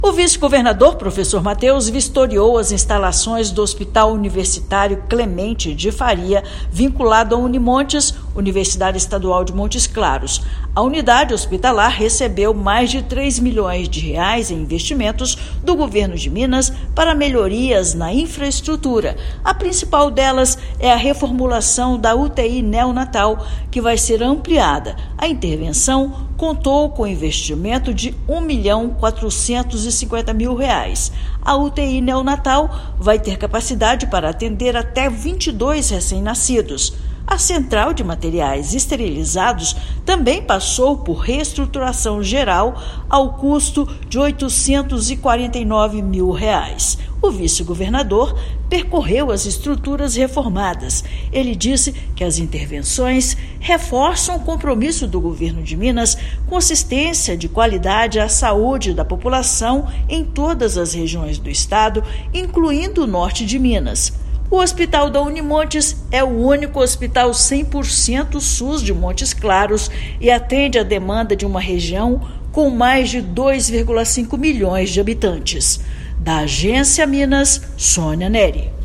[RÁDIO] Vice-governador vistoria obras de ampliação do Hospital Universitário em Montes Claros
A unidade, que é referência na região, recebeu um investimento de R$ 1,45 milhão para expansão da UTI neonatal. Ouça matéria de rádio.